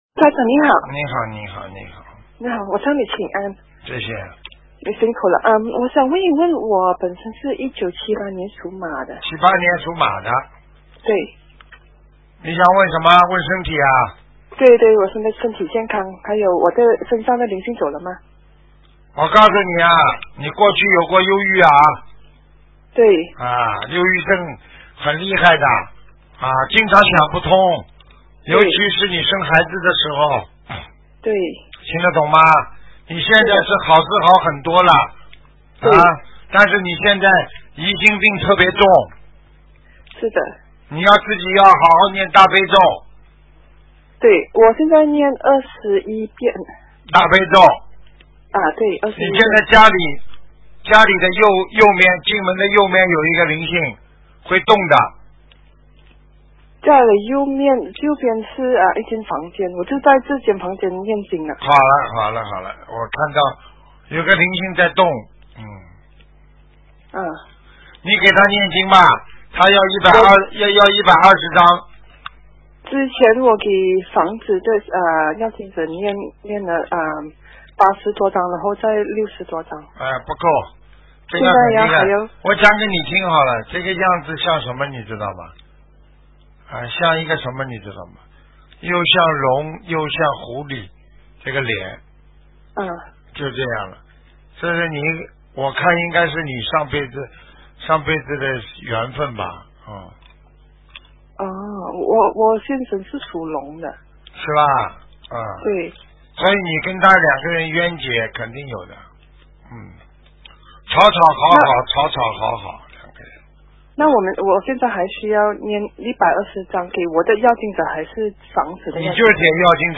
电台录音精选